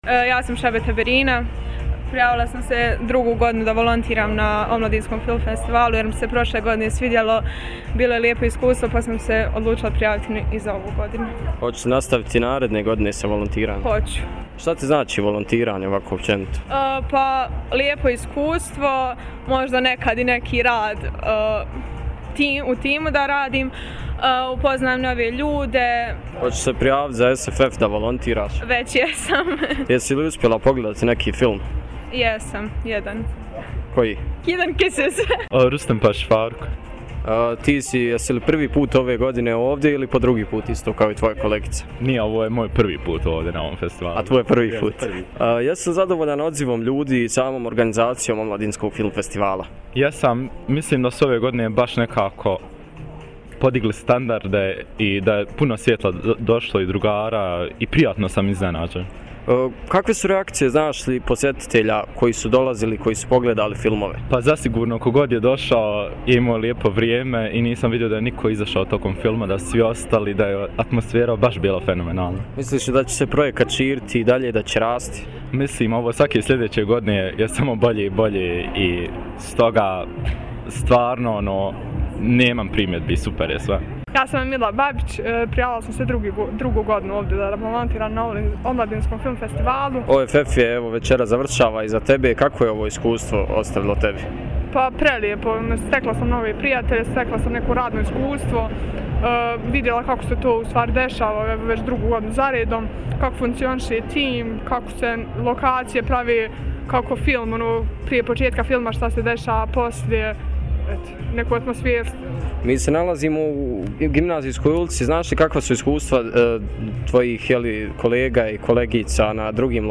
Donosimo vam djelić atmosfere sa zatvaranja i posljednjeg dana Omladinskog Film Festivala.
Trinaesti po redu OFF donio nam je čak 26 filmova od čega je 15 imalo svjetsku premijeru. Na Open Air-u u Gimnazijskoj ulici razgovarali smo sa volonterima na festivalu i pitali ih nekoliko zanimljivih pitanja.